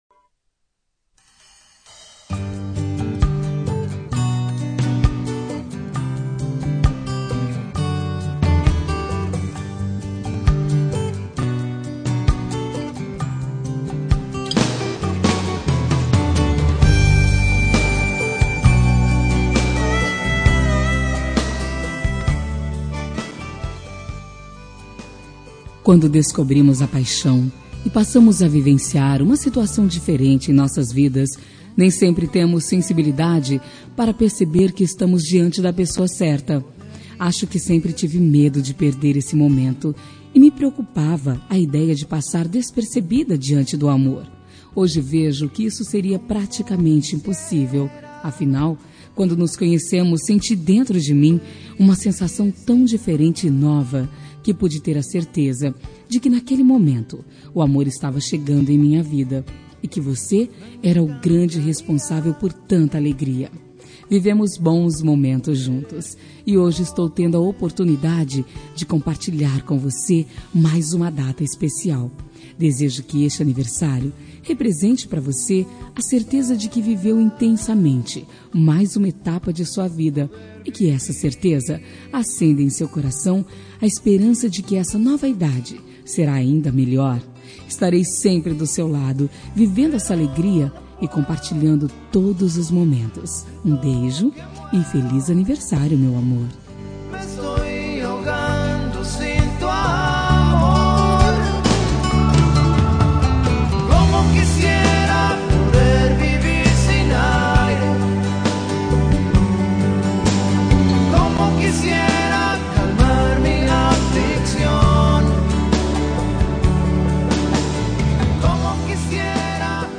Telemensagem de Aniversário Romântico – Voz Feminina – Cód: 1028